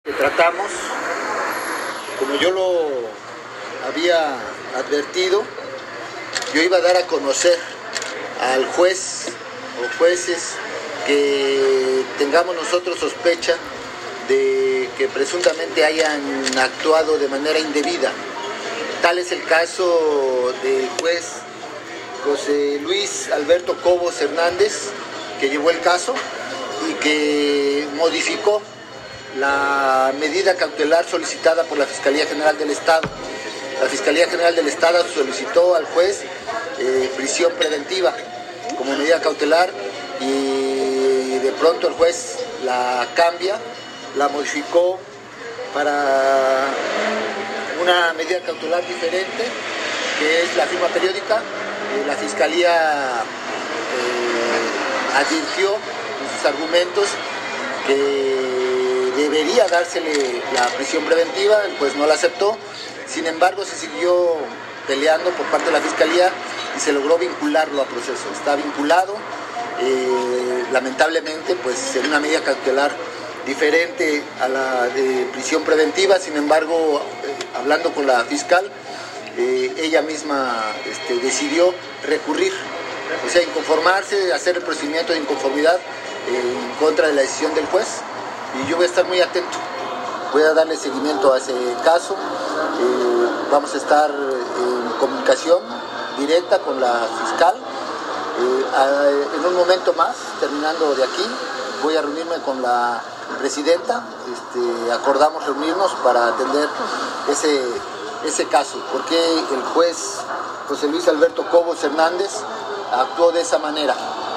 En entrevista en esta capital, el mandatario veracruzano anunció que solicitará al Consejo de la Judicatura realizar una investigación sobre la actuación del juez José Luis Alberto Cobos Hernández, quien a pesar de la solicitud de la Fiscalía General del Estado (FGE) se negó a dictar prisión preventiva en contra de Antonio “N” exdiputado local.